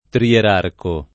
trierarco
vai all'elenco alfabetico delle voci ingrandisci il carattere 100% rimpicciolisci il carattere stampa invia tramite posta elettronica codividi su Facebook trierarco [ trier # rko ] o trierarca s. m. (stor.); pl.